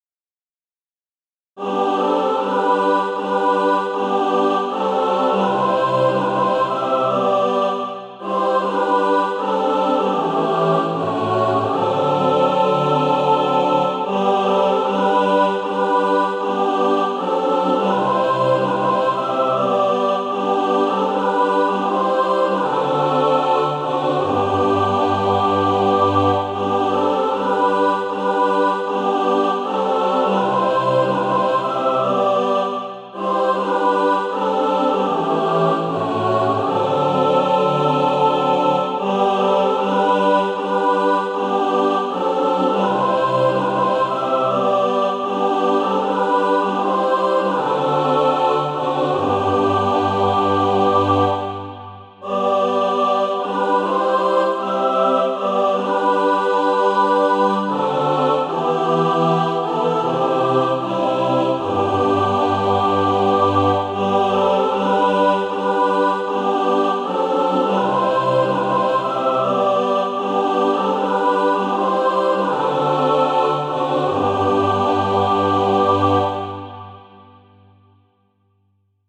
And also a mixed track to practice to
(SATB) Author